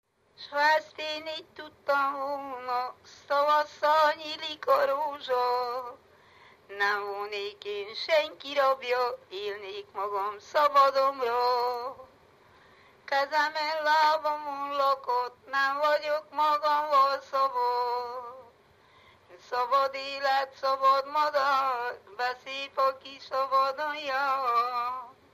Erdély - Csík vm. - Rakottyástelep (Gyimesbükk)
ének
Műfaj: Keserves
Stílus: 7. Régies kisambitusú dallamok
Szótagszám: 8.8